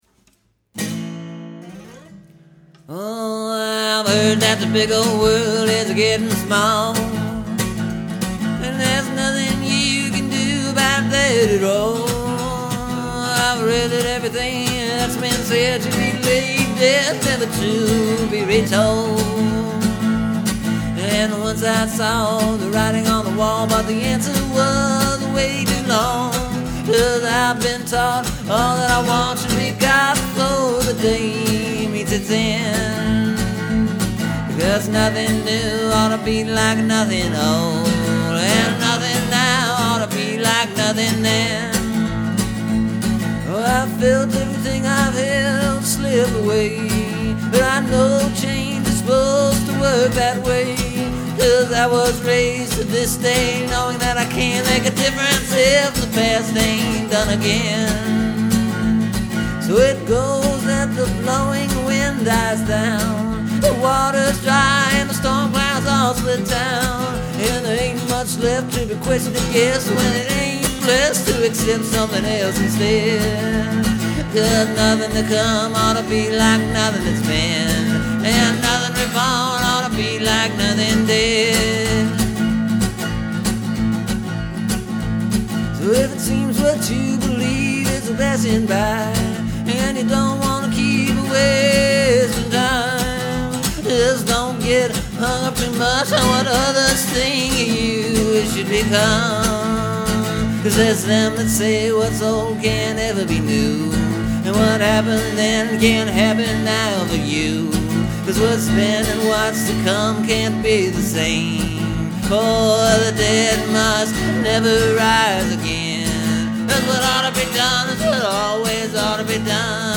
It’s faster and a little bluesier. And the repeating “oughta” lines I moved around a little bit, giving the feel of a chorus.